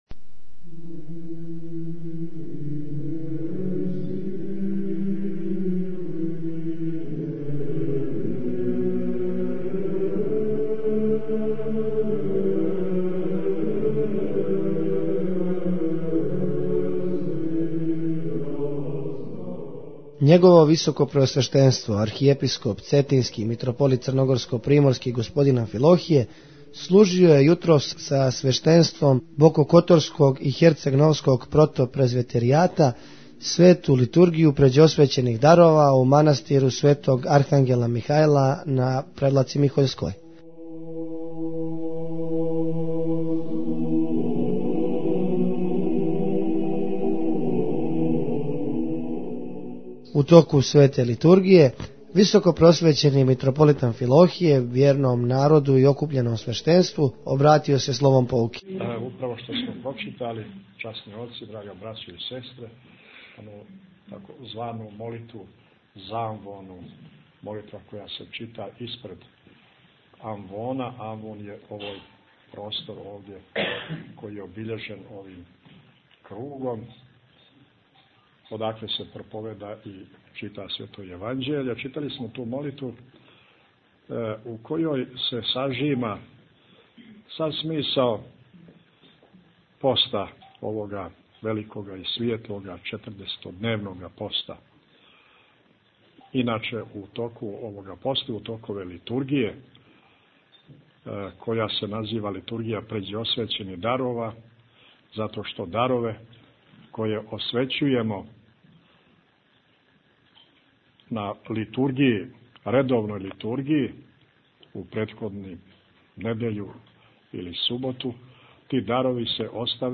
Митрополит Амфилохије служио Литургију Пређеосвећених Дарова у манастиру Михољска Превлака
У прилогу доносимо бесједу Његовог Високопресвештенства Митрополита Амфилохија.